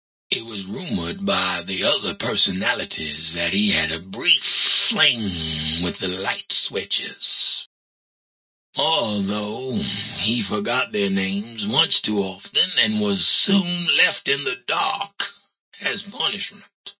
Category:Old World Blues endgame narrations Du kannst diese Datei nicht überschreiben.